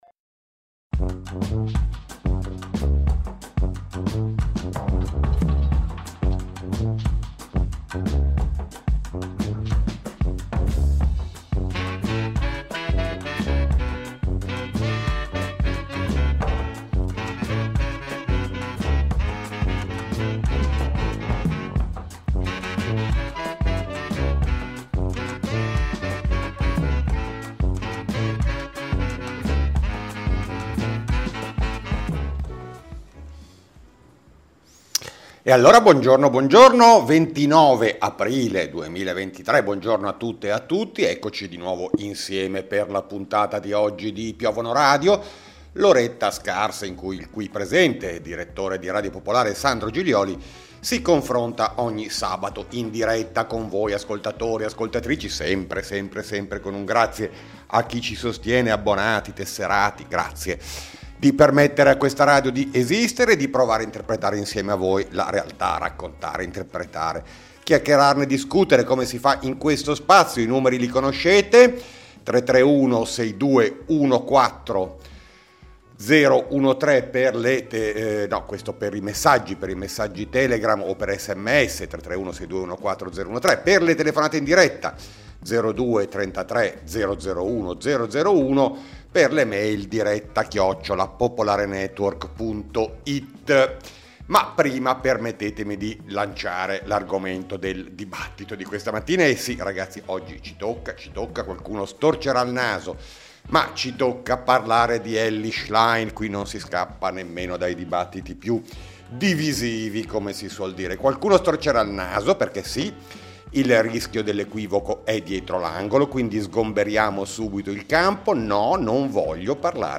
a confronto con ascoltatori e ascoltatrici.